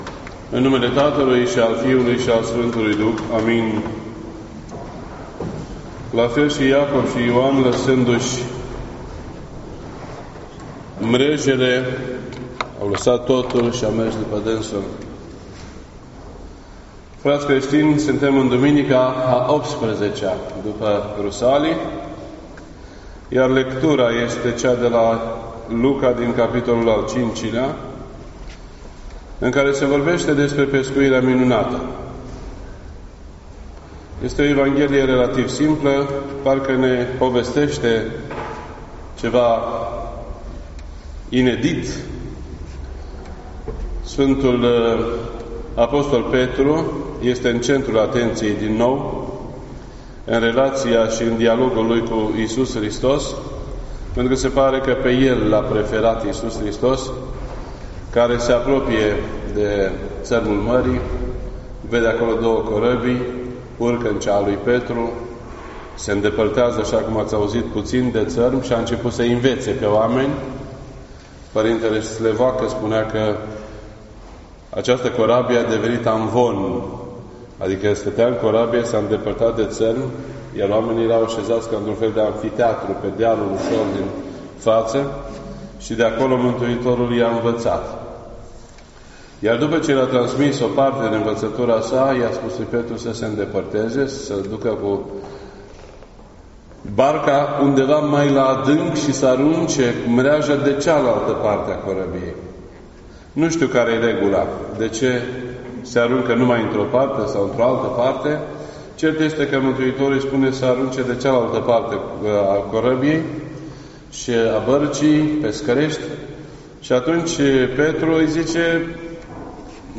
This entry was posted on Sunday, September 23rd, 2018 at 12:51 PM and is filed under Predici ortodoxe in format audio.